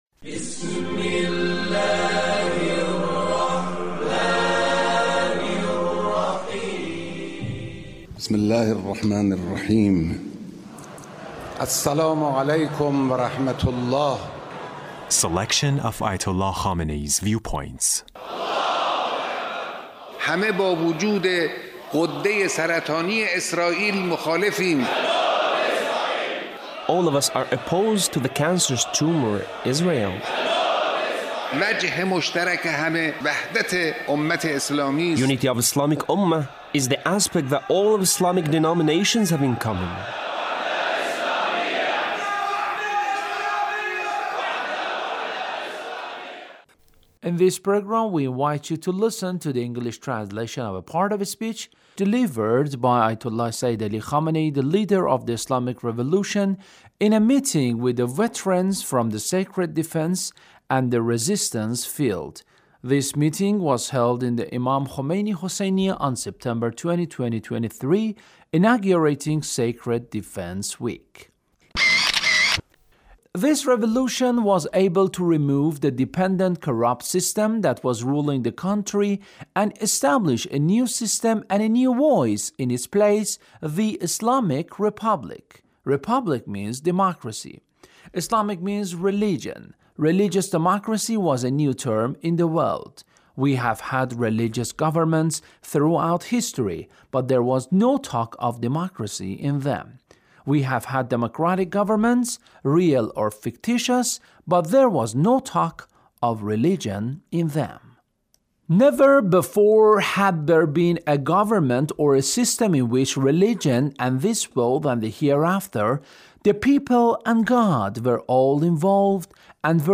Leader's Speech on Sacred Defense